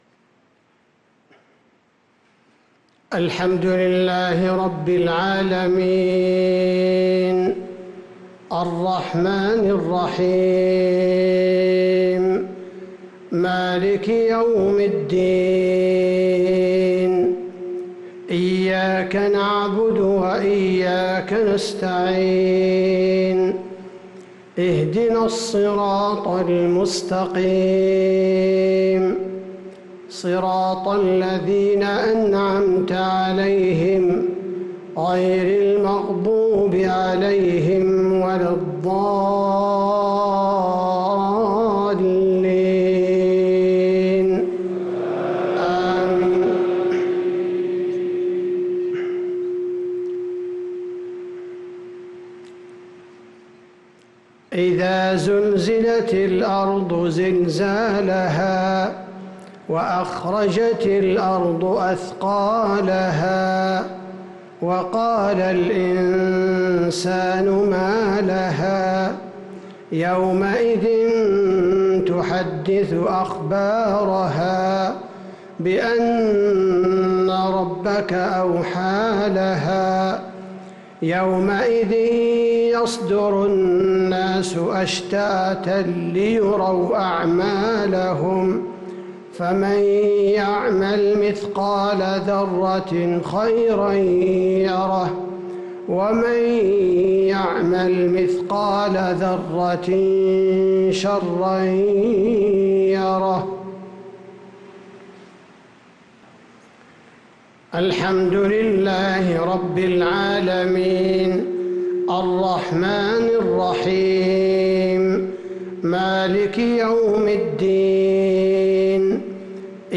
صلاة المغرب للقارئ عبدالباري الثبيتي 16 ذو الحجة 1443 هـ
تِلَاوَات الْحَرَمَيْن .